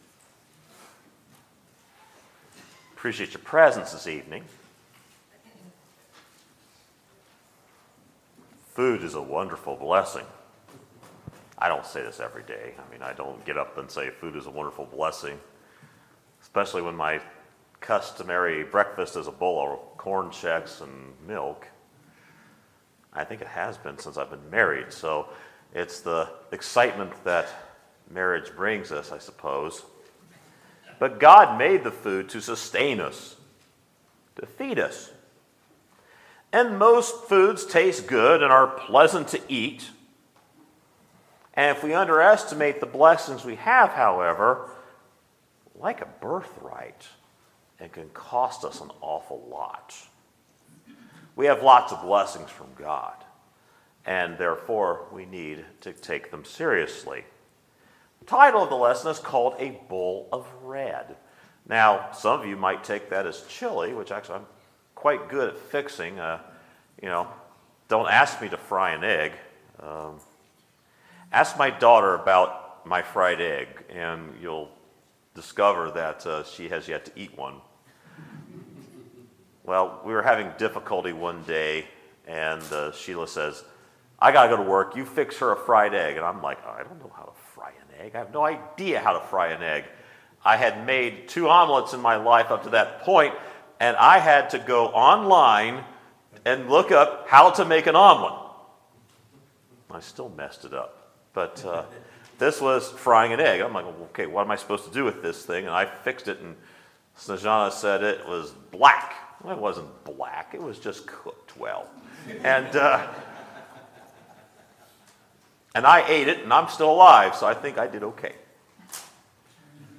Sermons, October 8, 2017